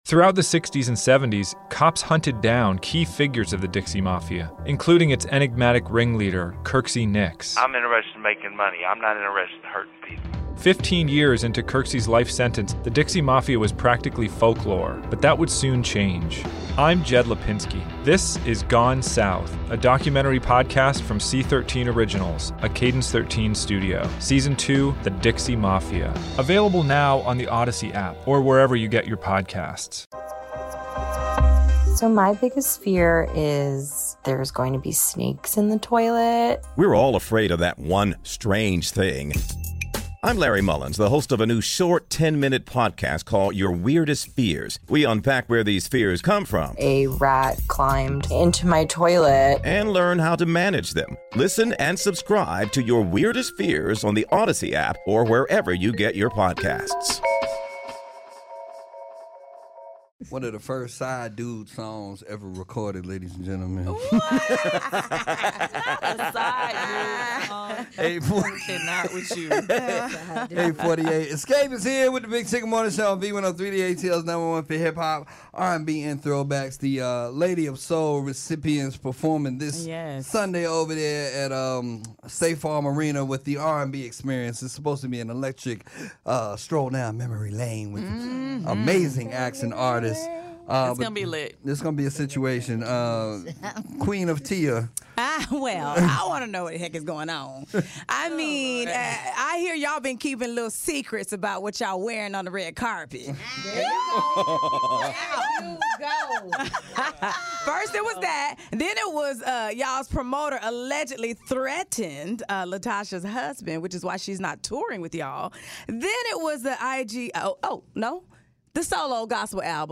Kandi Burruss, Tamika Scott, and Tamika ‘Tiny’ Harris opened up about the recent drama surrounding their group during an interview on The Big Tigger Show.